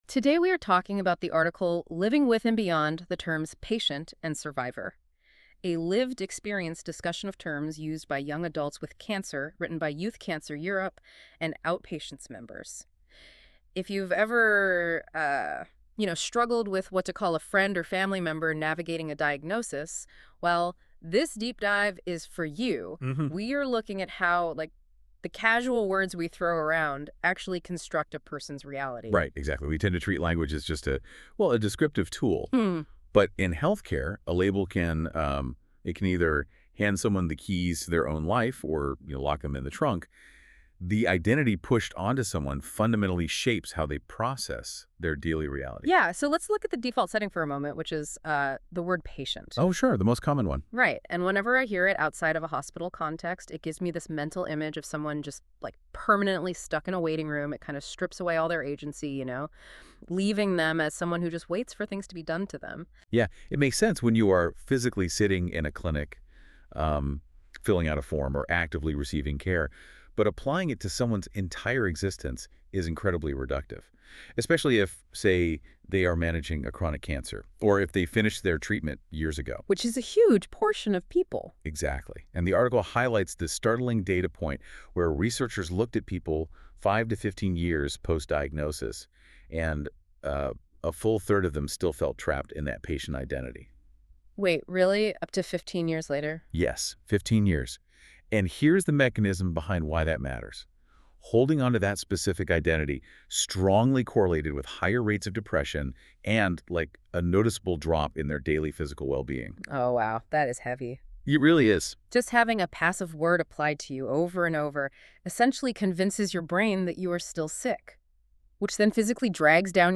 An AI-generated audio version of the article is available for those who prefer to engage with the content in a listening format.
The podcast is automatically generated from the published article and is intended as a listening aid.